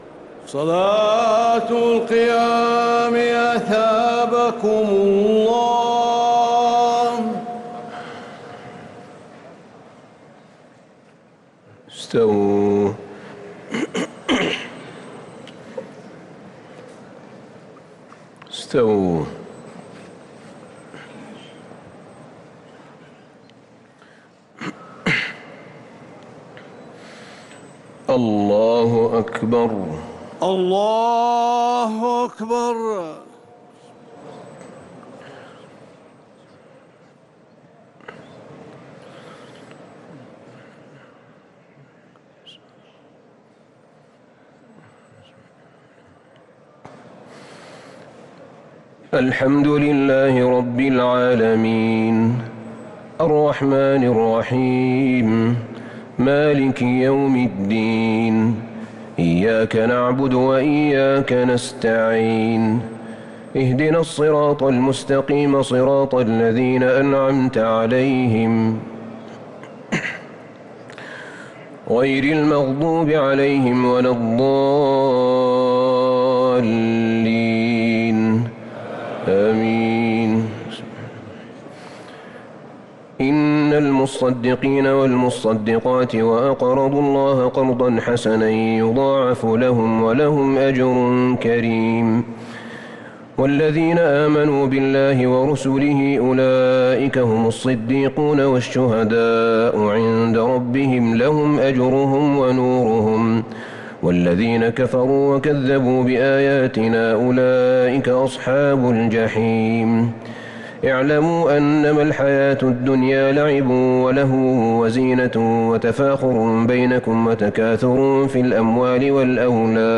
صلاة التراويح ليلة 28 رمضان 1443 للقارئ أحمد بن طالب حميد - الثلاث التسليمات الأولى صلاة التراويح